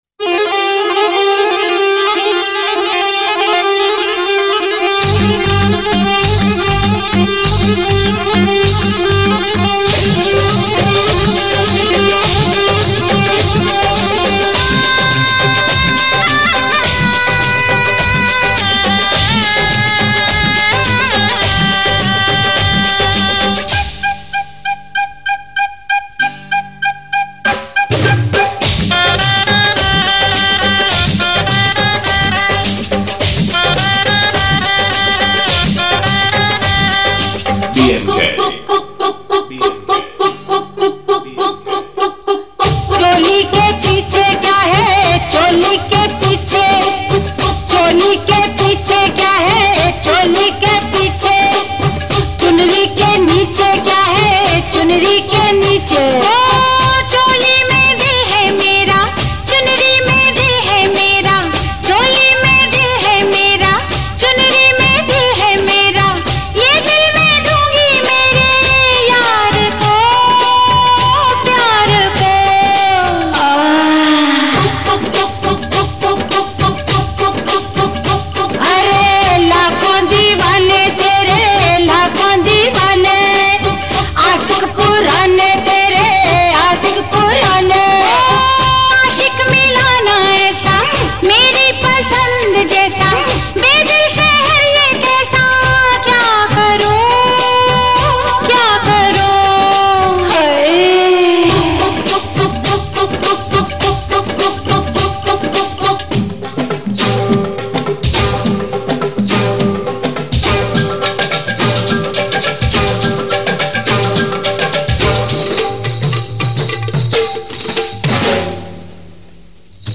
Mela Competition Filter Song